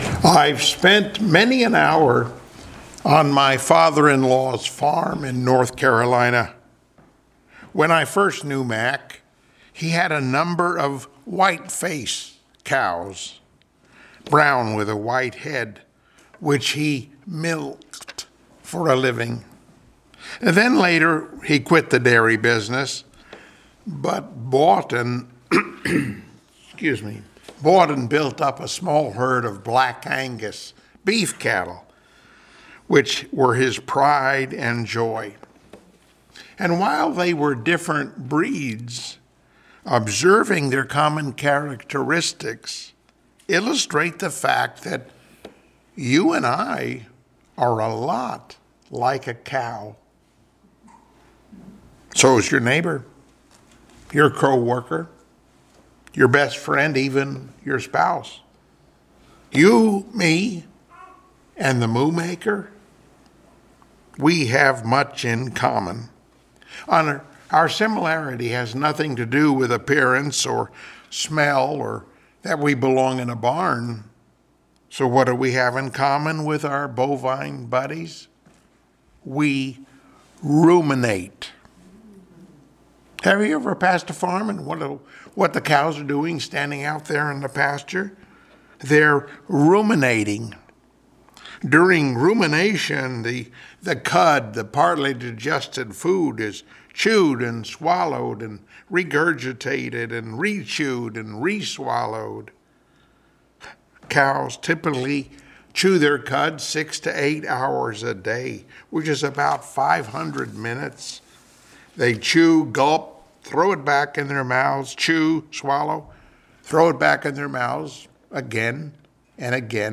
Ephesians 4:23-24 Service Type: Sunday Morning Worship Topics: A lesson in Thinking , Biblical Meditation , The Mind of Christ , We are Like Cows?